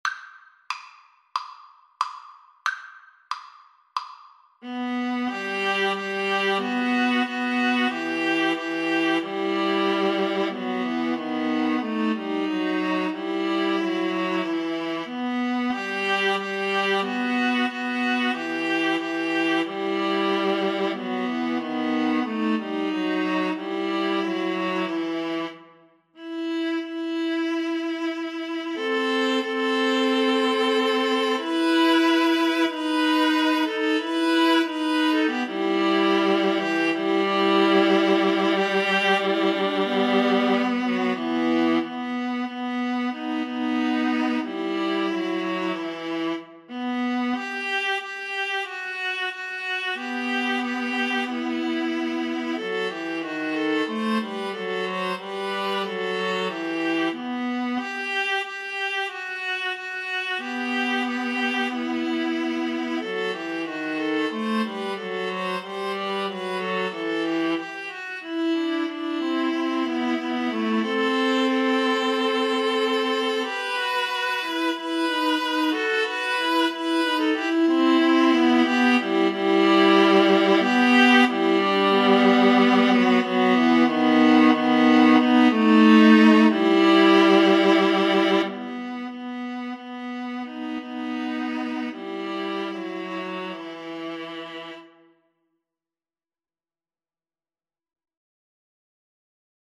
Viola 1Viola 2Viola 3
Traditional Music of unknown author.
"Go Down Moses" is an American Negro spiritual.
Arrangement for Viola Trio
E minor (Sounding Pitch) (View more E minor Music for Viola Trio )
4/4 (View more 4/4 Music)
Andante cantabile ( = c. 92)